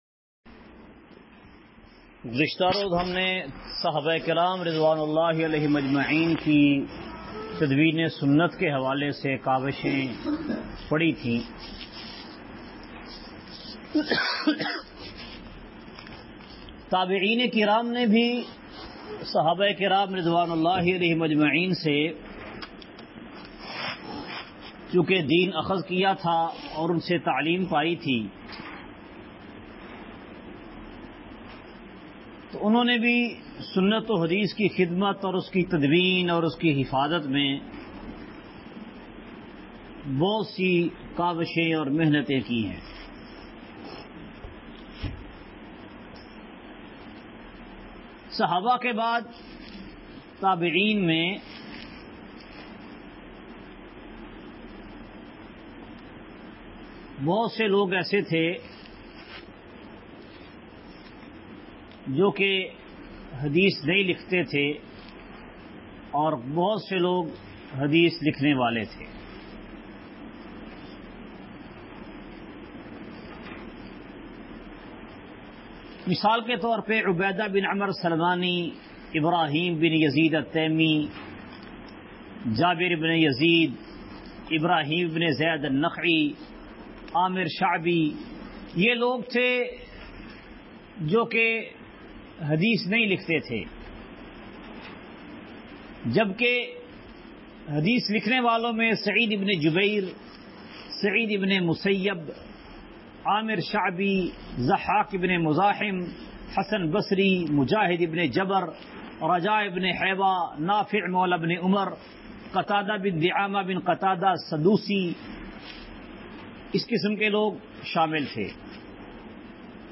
دوسری صدی سے اب تک تدوین حدیث سبق کا خلاصہ دوسری صدی ہجری سے حدیث کی تدوین کا دور شروع ہوا اور تا حال جاری وساری ہے تحميل mp3 × الحمد لله رب العالمين، والصلاة والسلام على سيد الأنبياء والمرسلين، أما بعد!